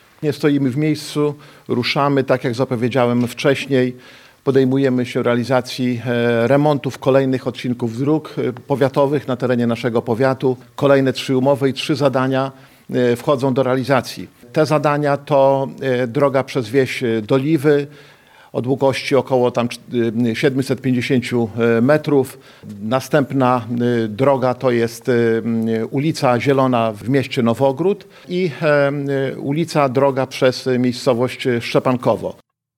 Starosta łomżyński Lech Szabłowski podkreślił, że to kolejne odcinki dróg powiatowych, które doczekają się remontu.